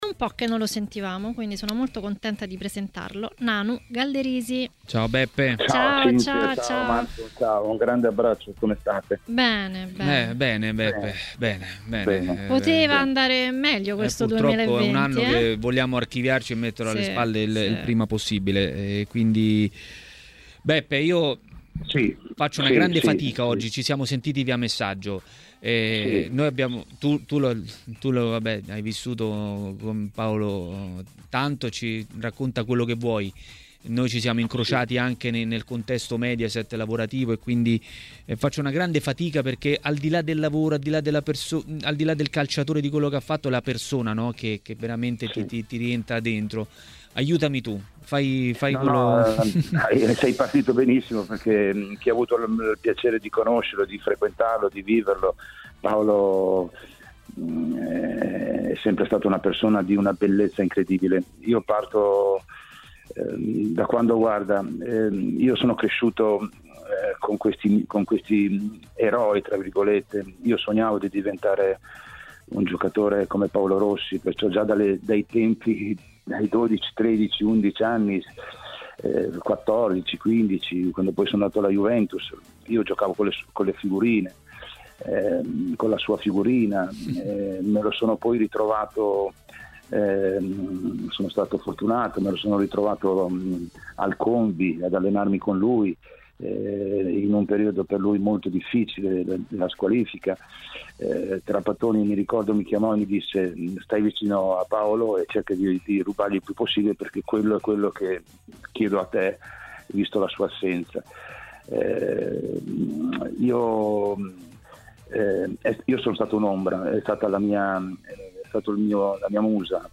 L'ex calciatore e tecnico Giuseppe "Nanu" Galderisi a TMW Radio, durante Maracanà, ha ricordato Paolo Rossi, scomparso oggi, 10 dicembre, all'età di 64 anni.